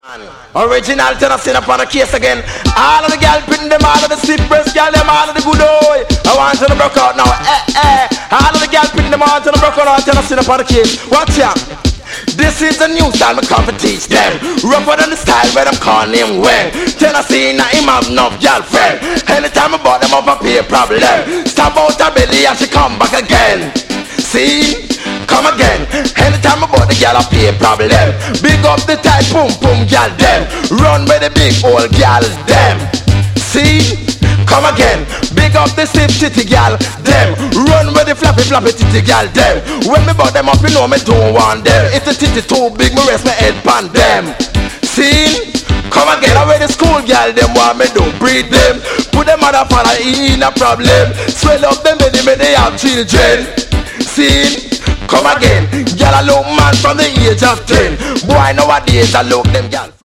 Categories: Dub. Roots. Reggae. Calypso. Ska. 2 Tone.